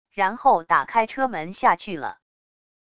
然后打开车门下去了wav音频生成系统WAV Audio Player